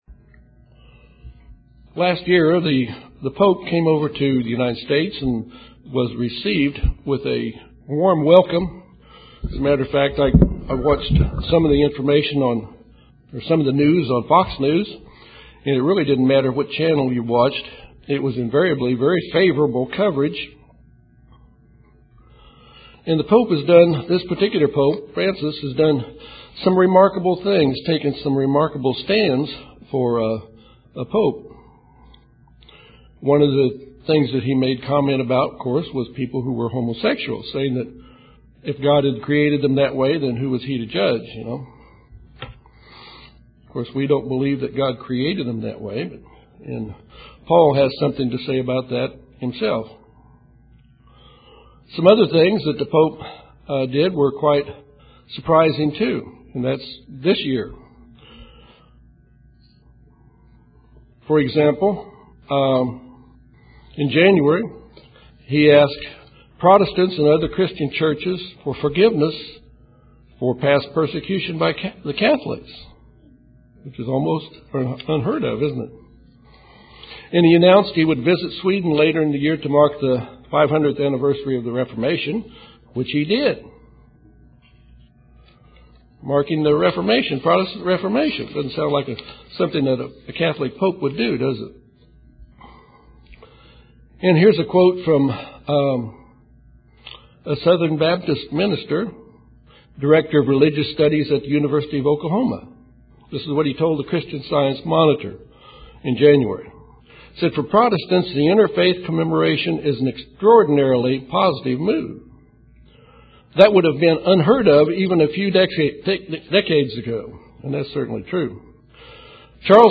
Did Jesus give Peter the key to the Kingdom of God? This sermon examines what the scripture says about who has the key to the Kingdom of God.